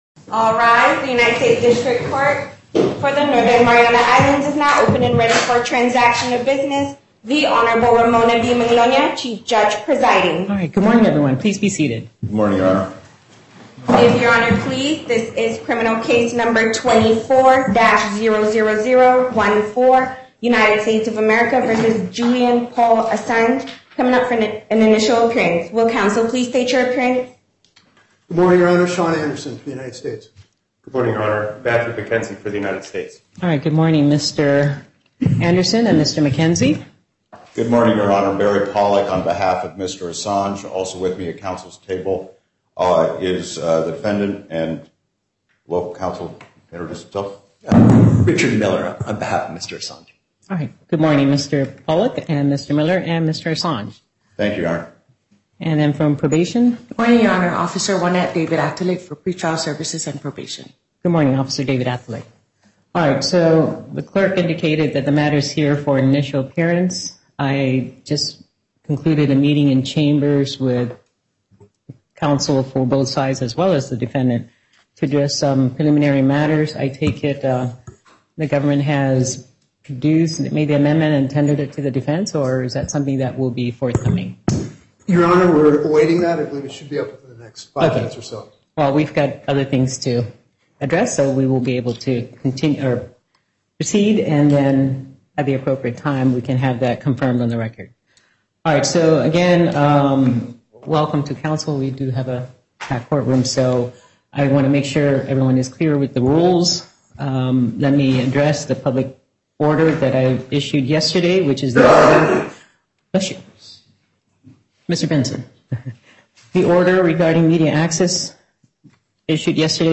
The audio files from Assange’s hearing were originally posted by Cryptome as released by the court, as PDFs with the MP3s attached.